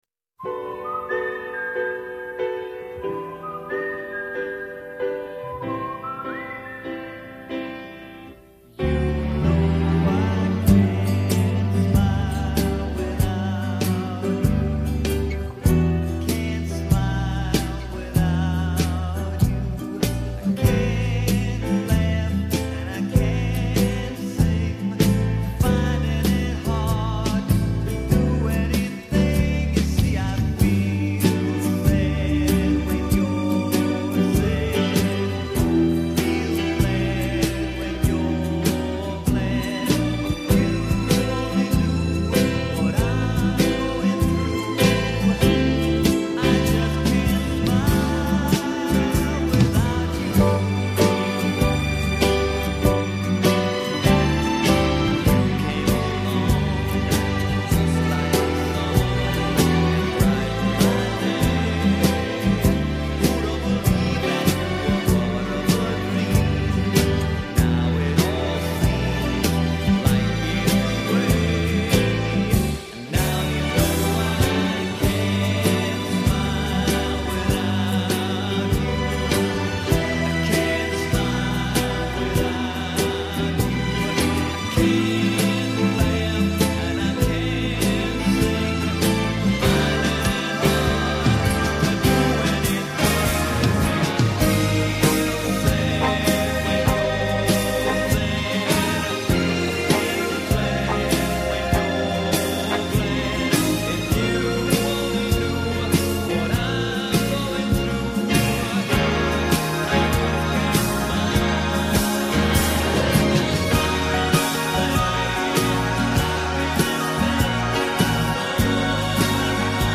(Karaoke Version)